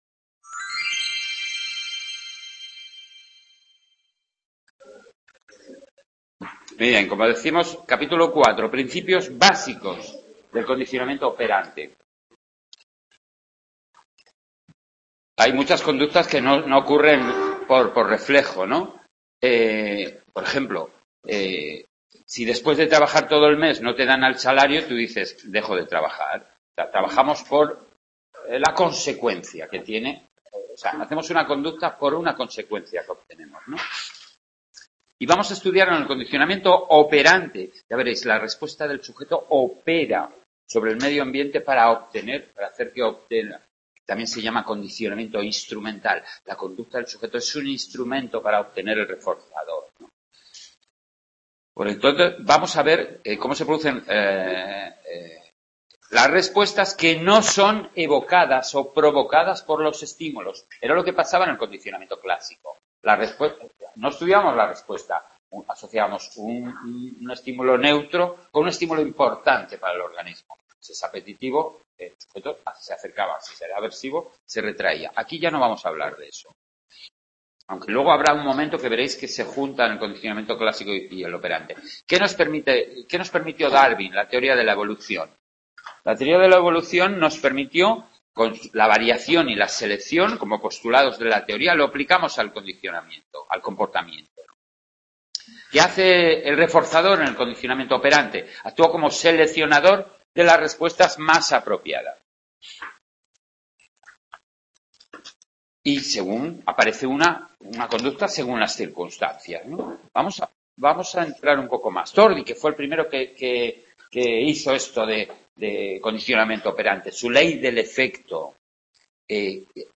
Comienzo del Tema 4 (1ª parte): Condicionamiento operante, de la asignatura de Psicología del Aprendizaje, realizada en el Aula de Sant Boi